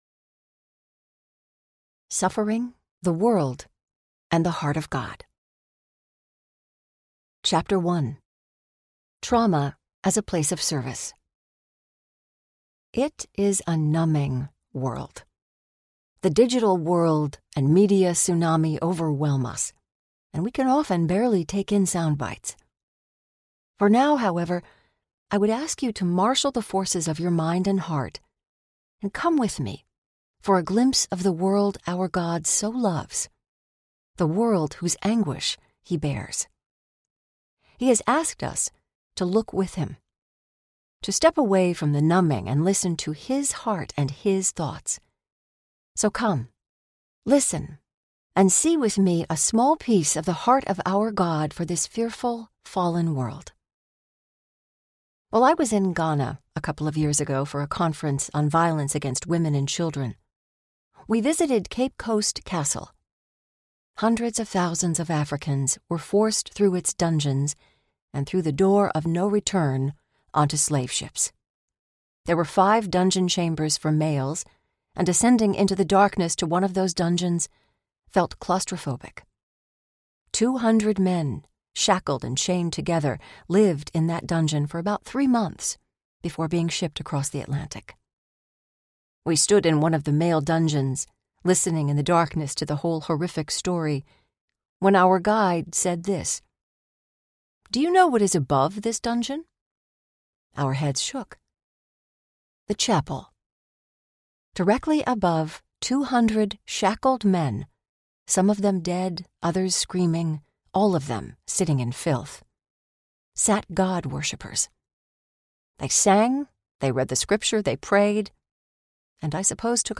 Suffering and the Heart of God Audiobook
Narrator
13.3 Hrs. – Unabridged